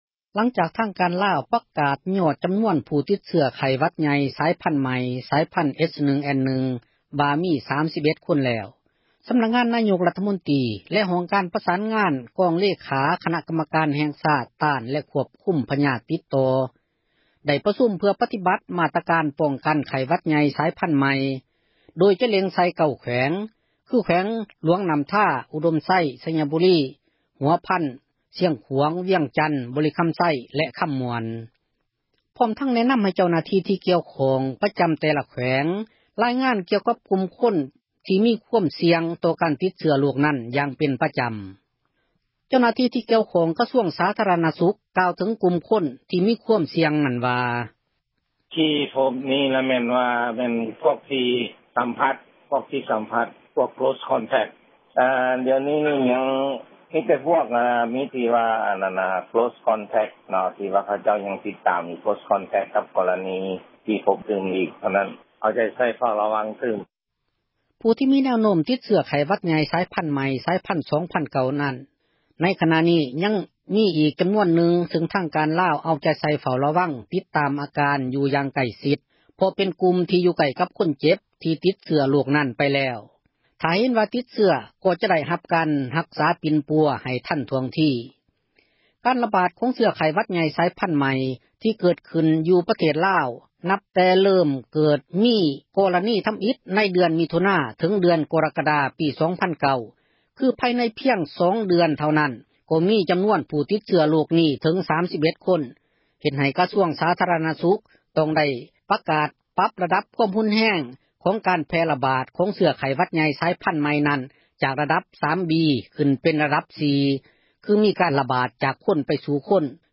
ເຈົ້າໜ້າທີ່ ທີ່ກ່ຽວຂ້ອງ ກະຊວງ ສາທາຣະນະສຸກ ກ່າວເຖິງກຸ່ມຄົນ ທີ່ມີຄວາມສ່ຽງ ນັ້ນວ່າ: